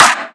• Trap Steel Snare Drum Sample A# Key 382.wav
Royality free snare one shot tuned to the A# note.
trap-steel-snare-drum-sample-a-sharp-key-382-QKX.wav